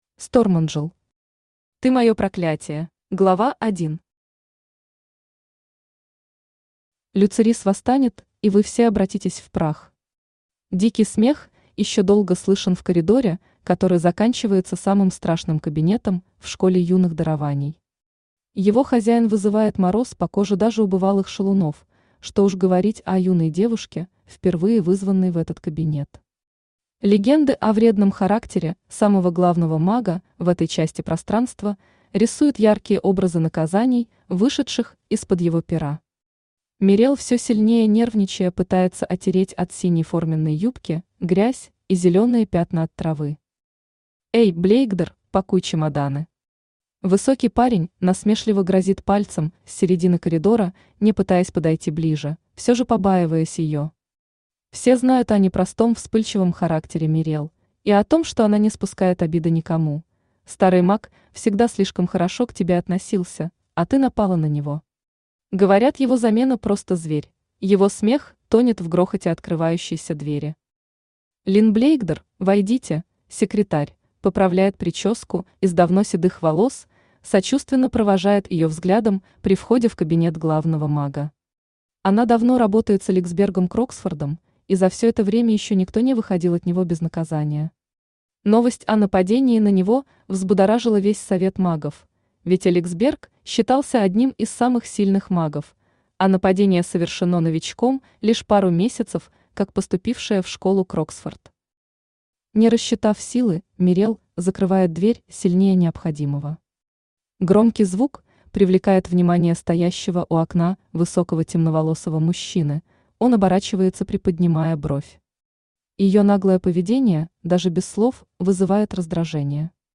Аудиокнига Ты мое проклятие | Библиотека аудиокниг
Читает аудиокнигу Авточтец ЛитРес.